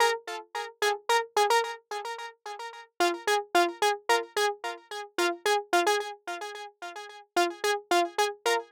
03 Solo Synth PT2.wav